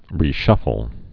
(rē-shŭfəl)